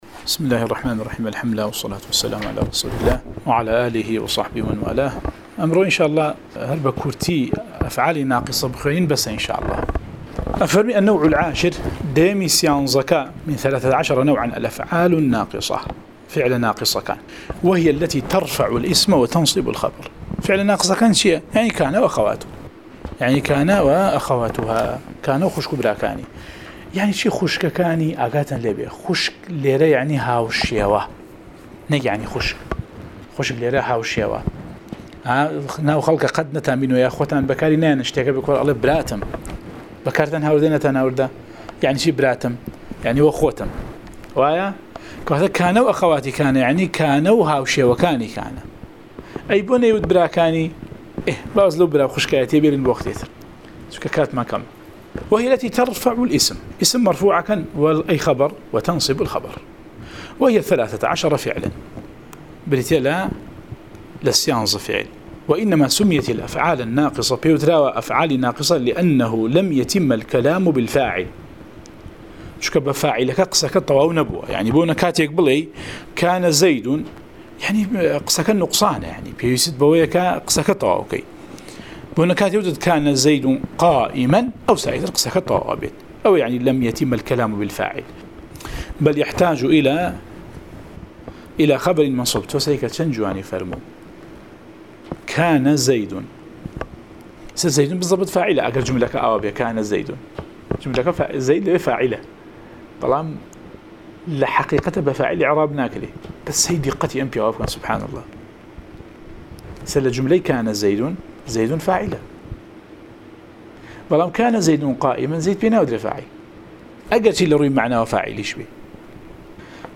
11 ـ شەرحی العوامل المائة، (عوامل الجرجانی) (نوێ) وانەی دەنگی: - شرح عوامل المائة (عوامل الجرجاني)